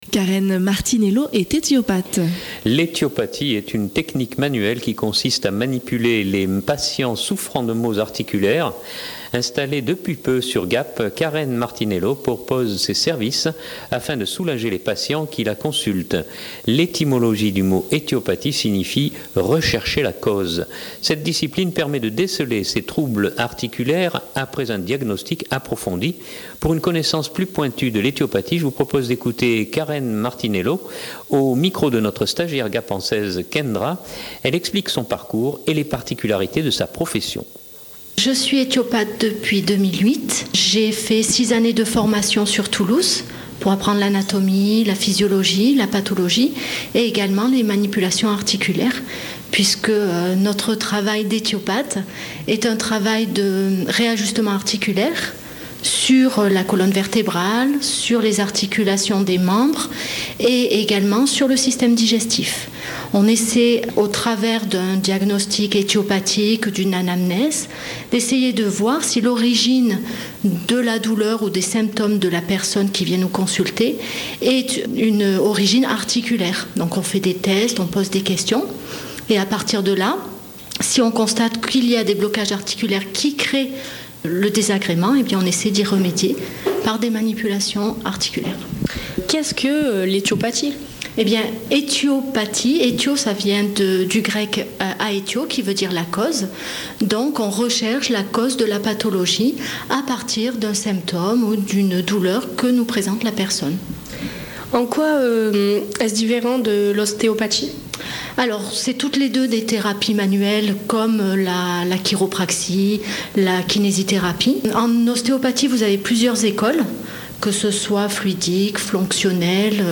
Elle explique son parcours et les particularités de sa profession. etiopathie.mp3 (10.5 Mo)